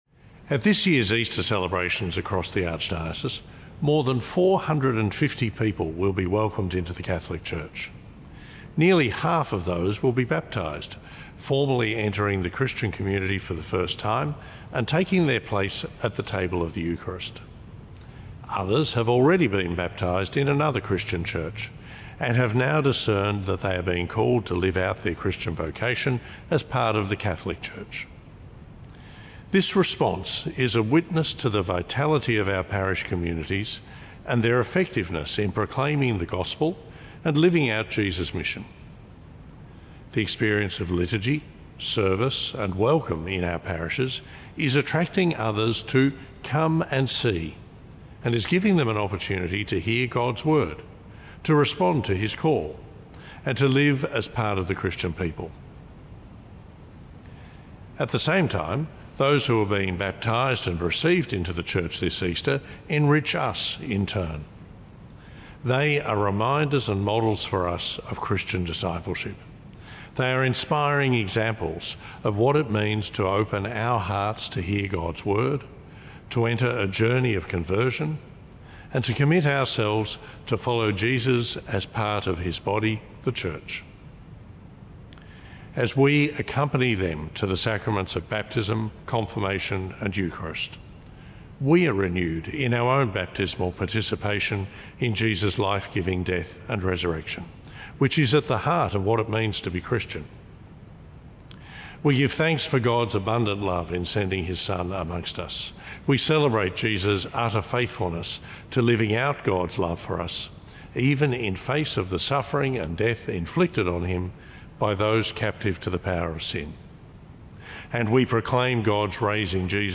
Archbishop Shane Mackinlay's Easter Message